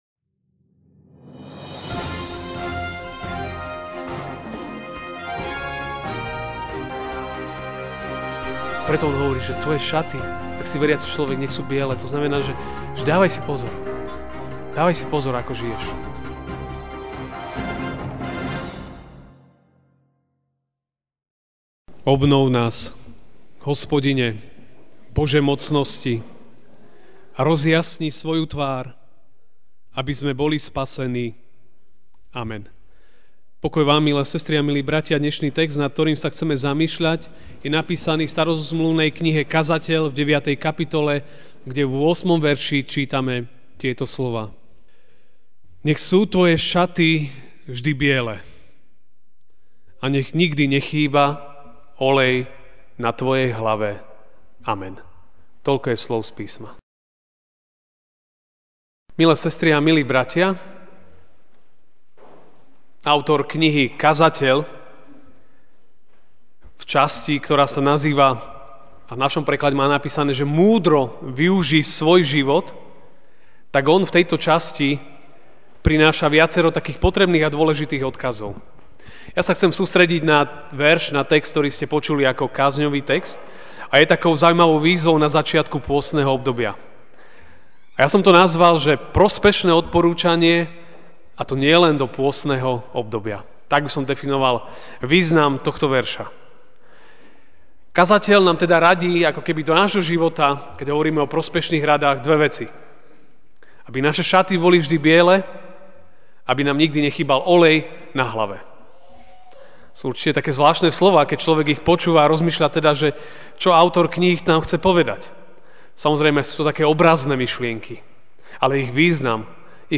MP3 SUBSCRIBE on iTunes(Podcast) Notes Sermons in this Series Ranná kázeň: Prospešné odporúčanie, nie len do pôstneho obdobia! (Kazateľ 9, 8) Nech sú tvoje šaty vždy biele a nech nikdy nechýba olej na tvojej hlave.